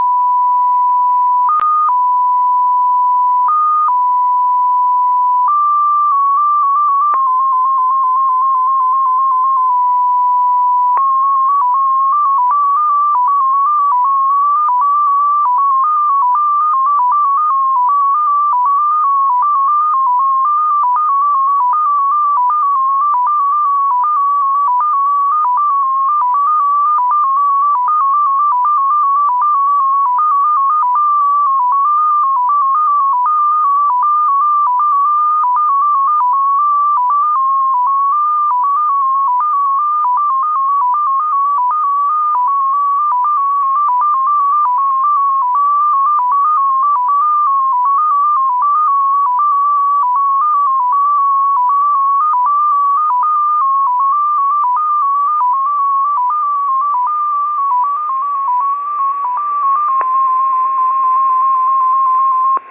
• Enigma Designation XPA2, also known as MFSK-16, CIS MFSK-14, and CIS MFSK-16, is a 14-tone MFSK signal said to have originated from Russian Intelligence and Foreign Ministry stations: